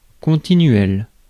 Ääntäminen
IPA : /kənˈtɪnjuəs/